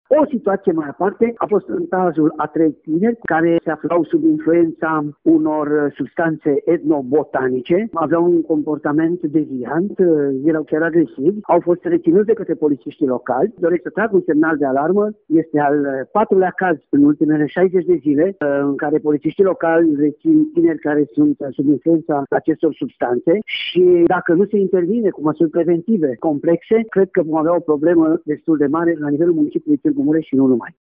Acesta este al patrulea caz de acest fel în ultimele două luni, spune șeful Poliției Locale din Tîrgu-Mureș, Valentin Bretfelean: